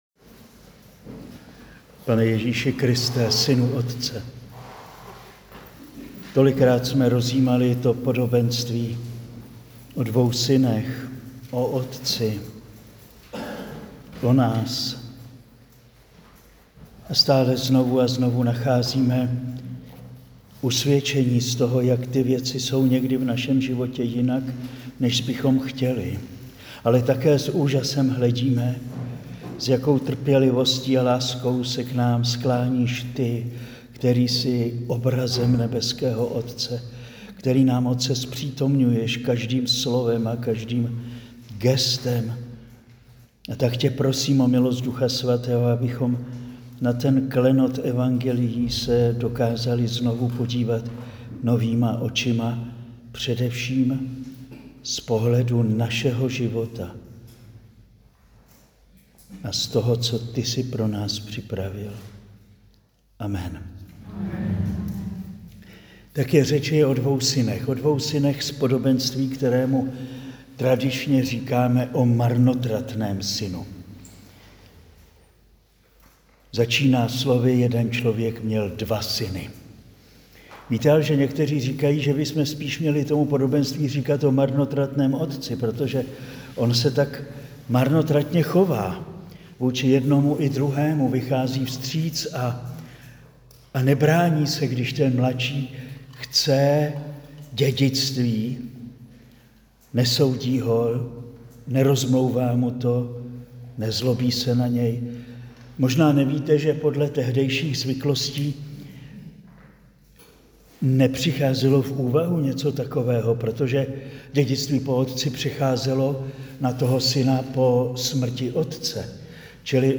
Přednáška zazněla na kurzu učednictví v květnu 2025